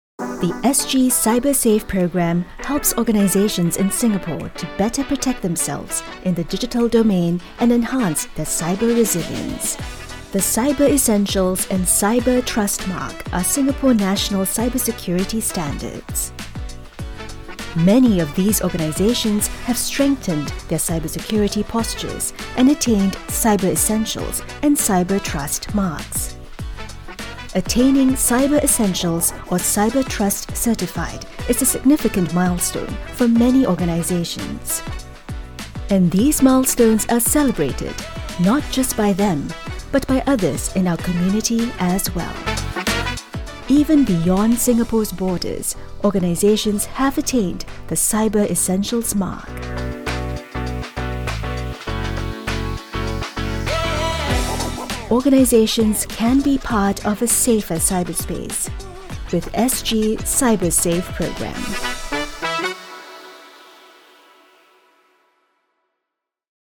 Guias de áudio
~ Uma dubladora calorosa, amigável e versátil com apelo global ~
Microfone Rode NT1 (Kit de estúdio NT1 AI-1 com interface de áudio), suporte antichoque SM6, fones de ouvido Shure SRH440A Gen 2, filtro pop, escudo de isolamento, manta de amortecimento de som e placas de feltro